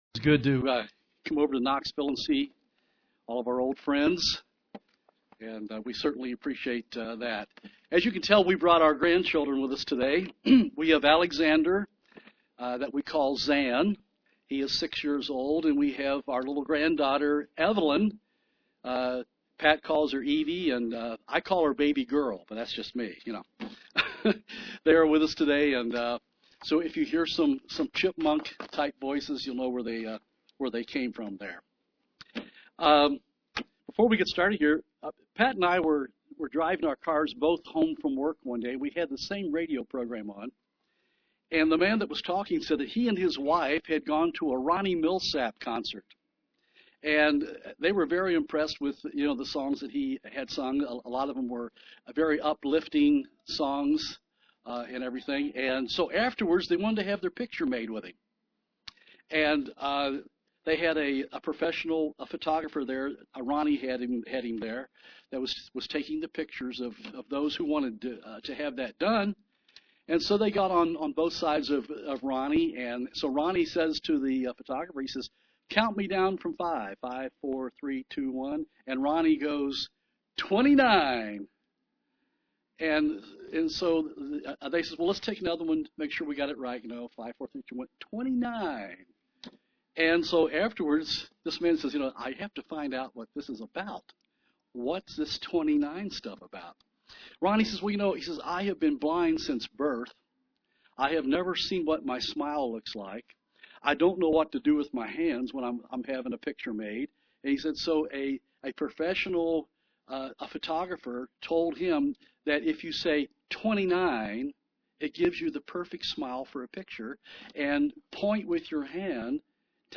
Print Life of the Apostle Peter UCG Sermon Studying the bible?